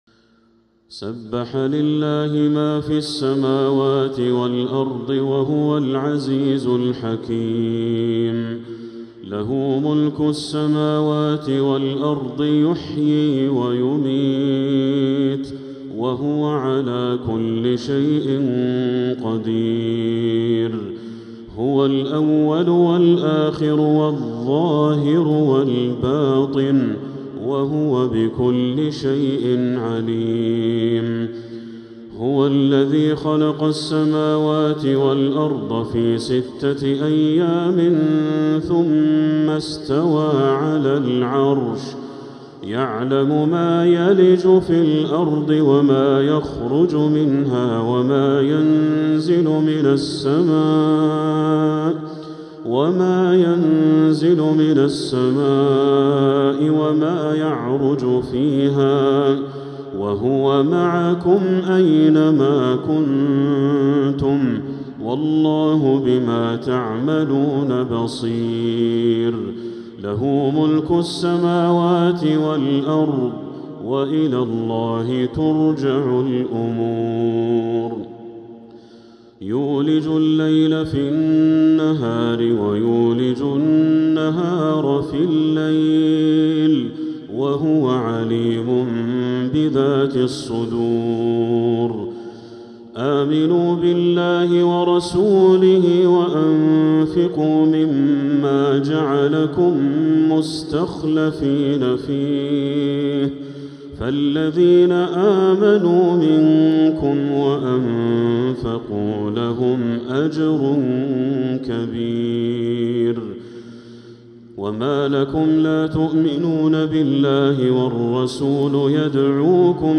من المسجد الحرام